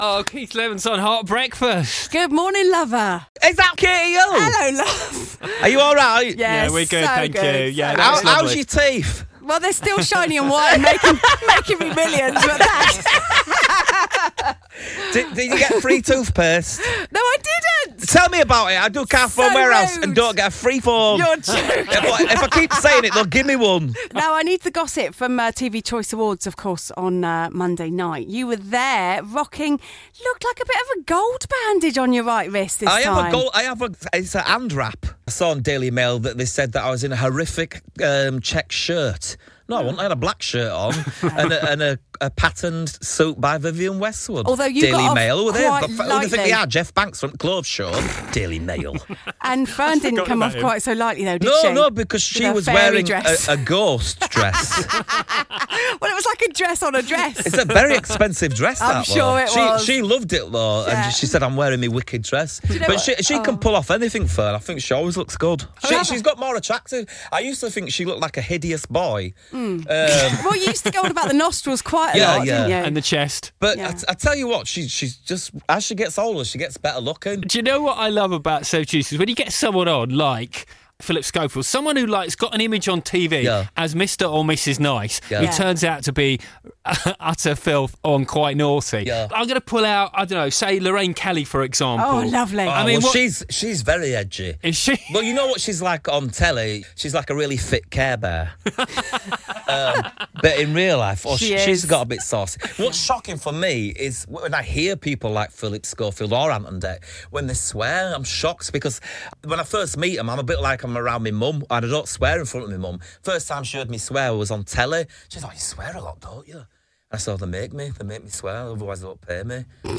Keith Lemon swings by Heart Breakfast to chat Celeb Juice, toothpaste and fit carebears